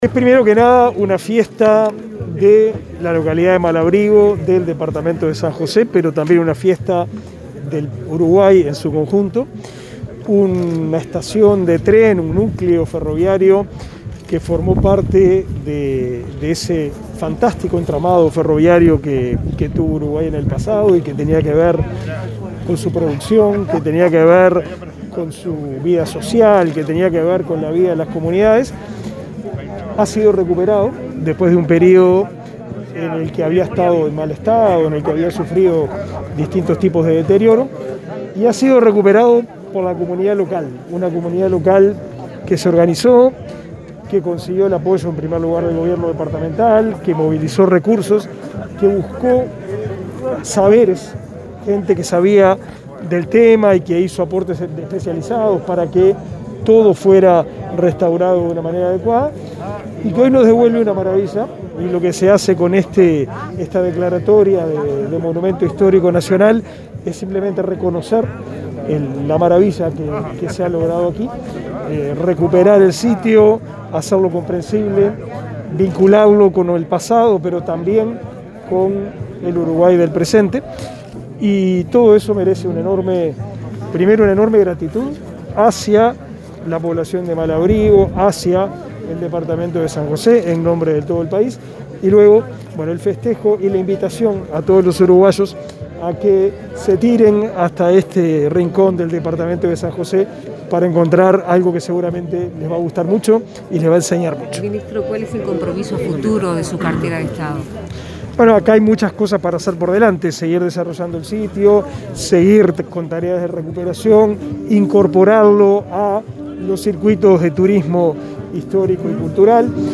Declaraciones de prensa del ministro de Educación y Cultura, Pablo da Silveira
Tras el acto de declaración de la estación de Mal Abrigo como Monumento Histórico Nacional, este 21 de agosto, el jerarca brindó declaraciones a los